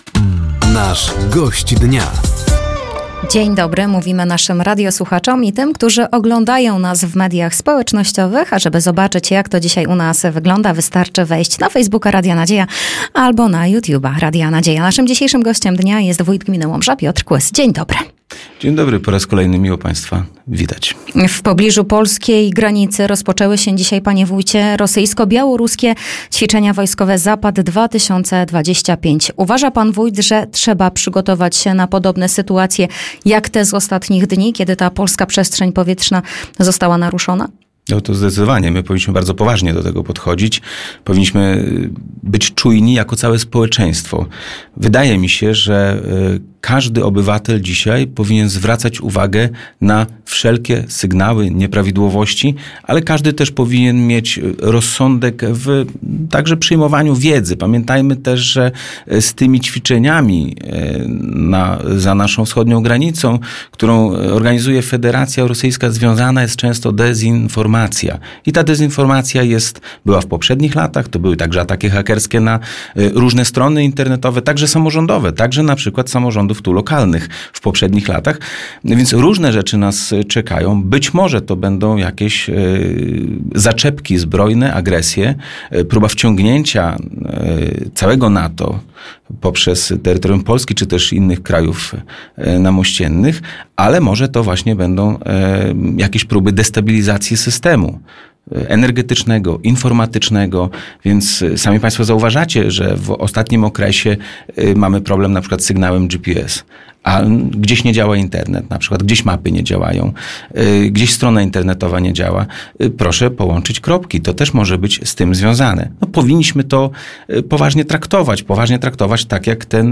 Rozpoczynające się przy polskiej granicy manewry ,,Zapad 2025″, wizyta premiera Donalda Tuska w Łomży, 25-lecie Związku Gmin Wiejskich Województwa Podlaskiego, czy Fundusz Sołecki – to główne tematy rozmowy z Gościem Dnia Radia Nadzieja.
Zapraszamy do wysłuchania i obejrzenia rozmowy z wójtem Gminy Łomża, Piotrem Kłysem.